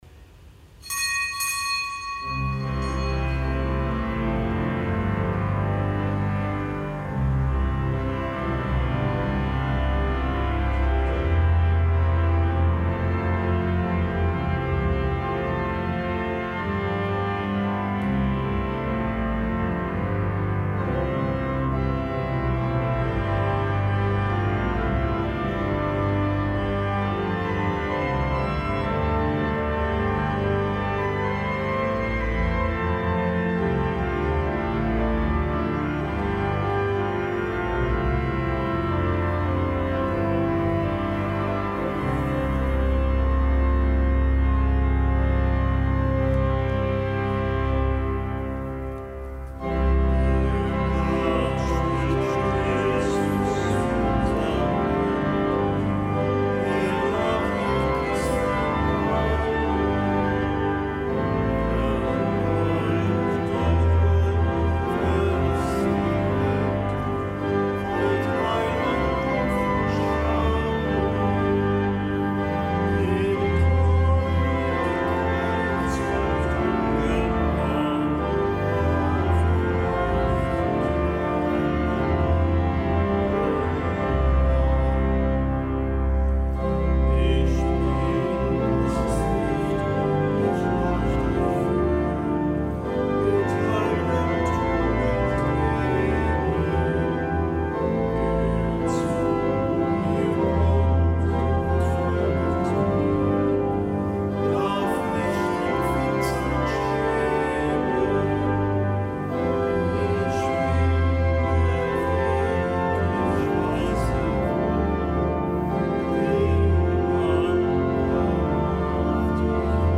Kapitelsmesse aus dem Kölner Dom am Gedenktag der Enthauptung Johannes‘ des Täufers.